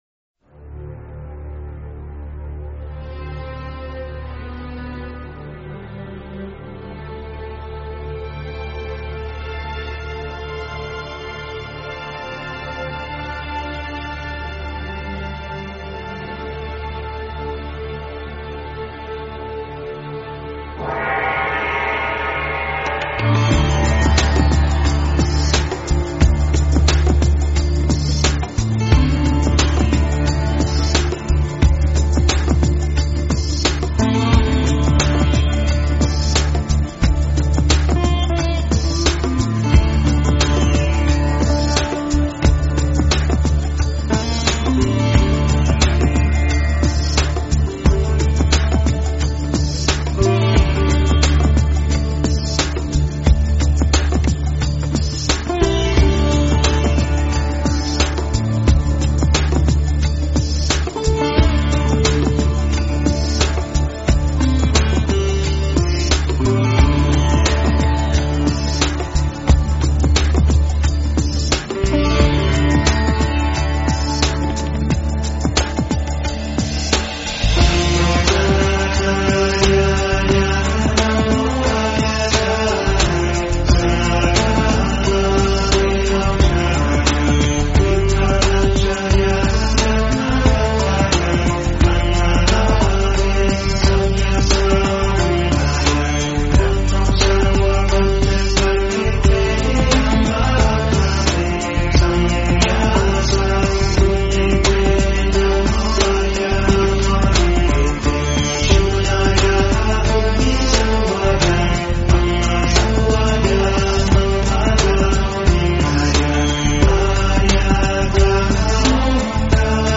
诵经
佛音 诵经 佛教音乐 返回列表 上一篇： 心经(粤语